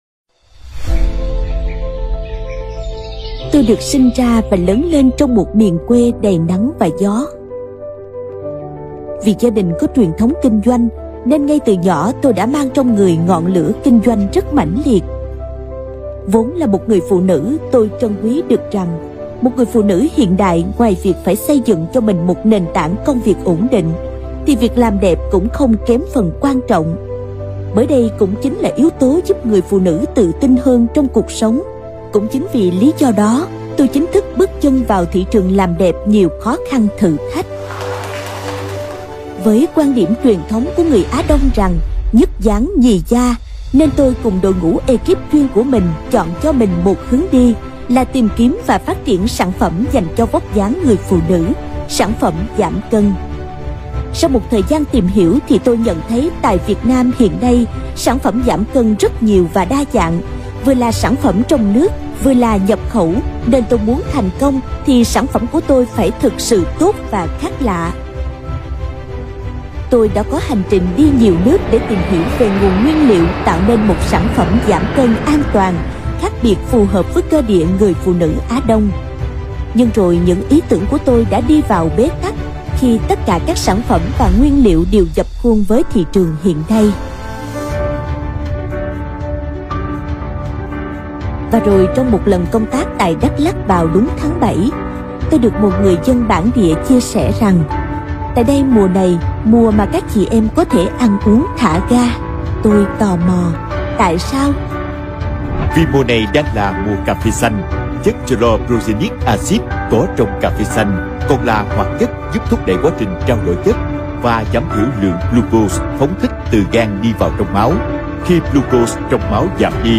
女越南1T-17 越南语女声 低沉|激情激昂|大气浑厚磁性|沉稳|娓娓道来|科技感|积极向上|时尚活力|神秘性感|亲切甜美|素人